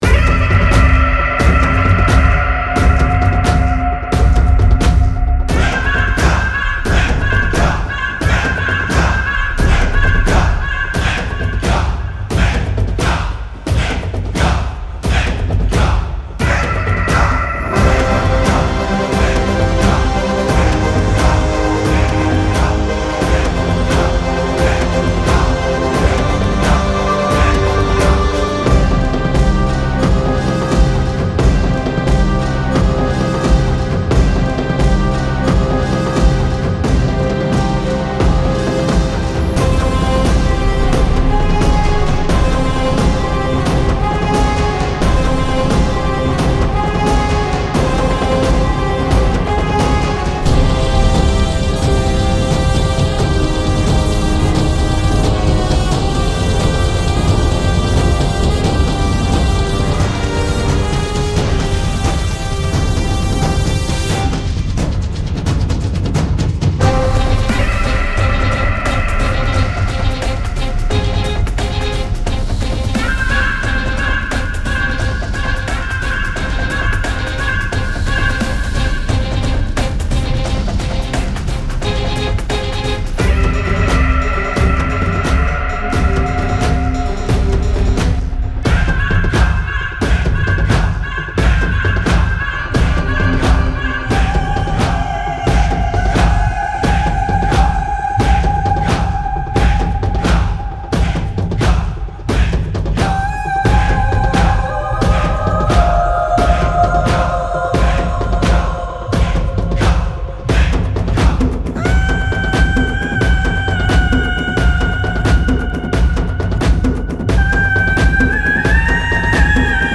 with the best mixing, vocal removing and quality